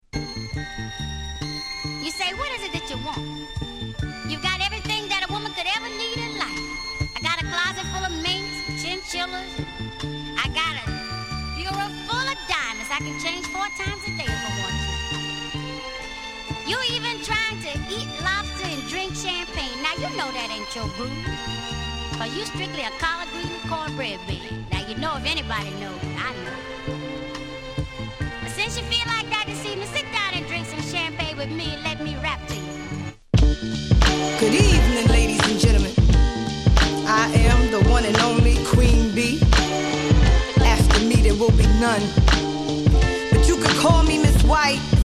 00's Smash Hit Hip Hop !!!